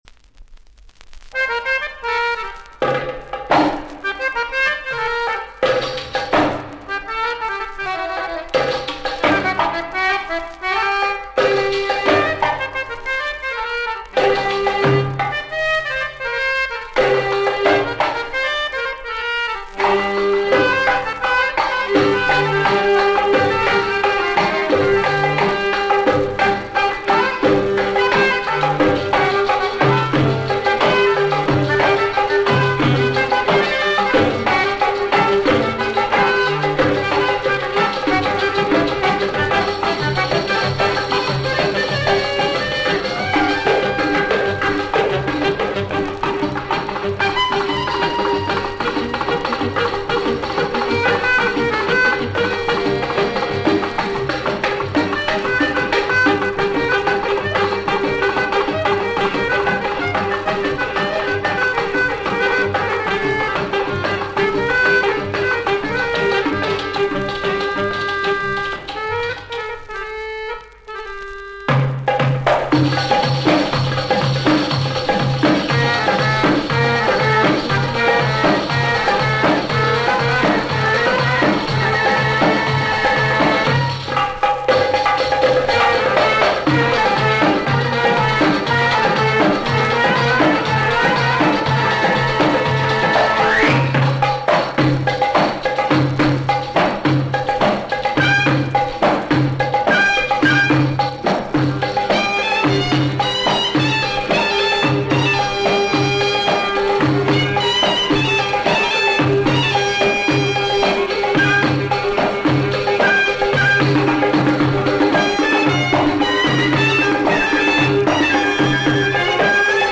Support : 45 tours 17 cm